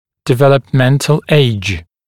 [dɪˌveləp’ment(ə)l eɪʤ][диˌвэлэп’мэнт(э)л эйдж]возраст развития (например, скелетного)